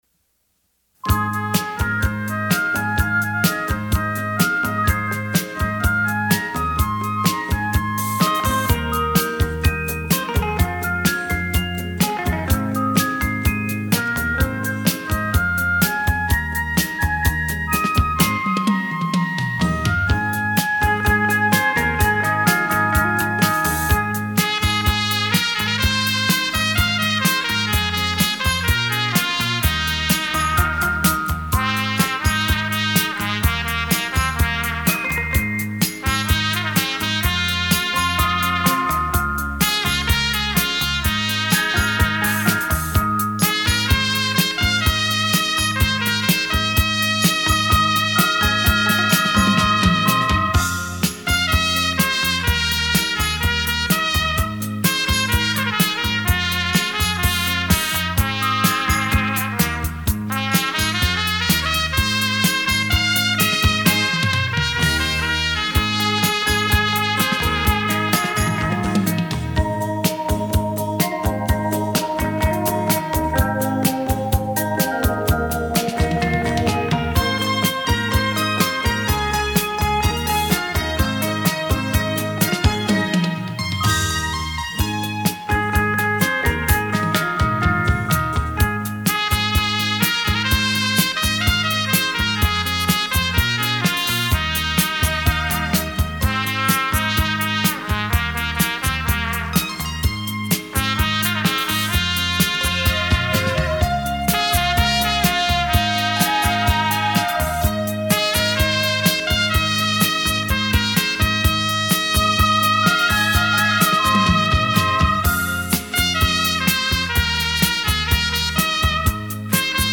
24bit 数位录音
录音：佳声录音室
闲适优雅的音符，完美传真的音质，兴来CD激光唱片，呈献在您的耳际
优美动听的旋律让人沉醉其中...